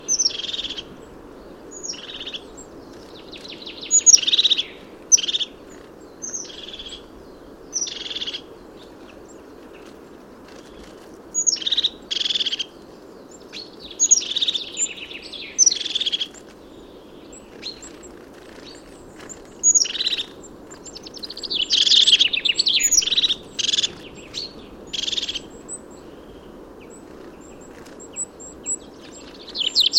Illustration mésange huppée
mesange-huppee.mp3